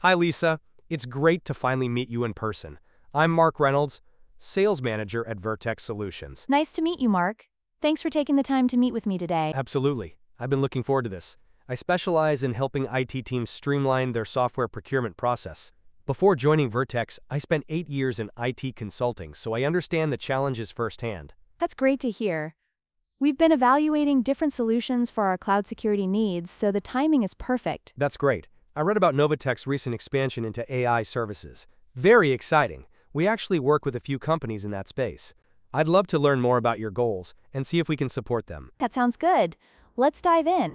レッスンでは、以下のような会話を聞いた後に、講師と内容についてディスカッションをします。